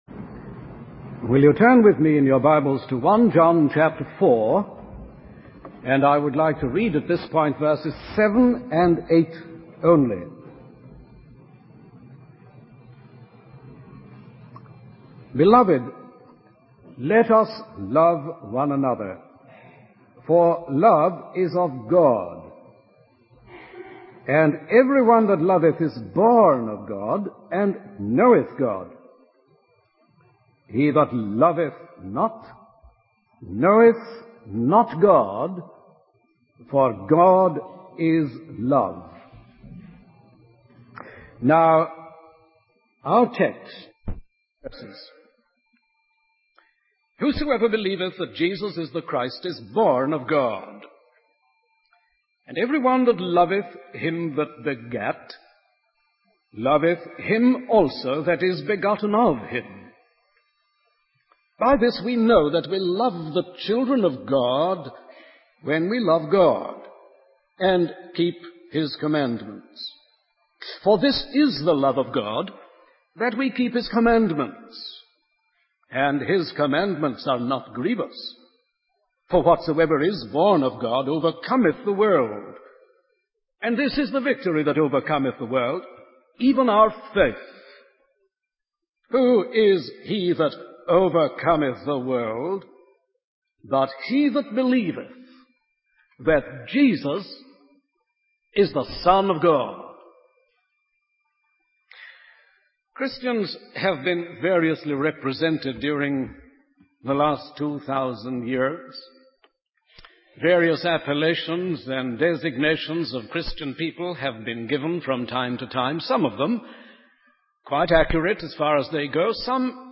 In this sermon, the preacher invites non-Christians to look at Jesus through the telescope of Scripture and see Him in the gospels.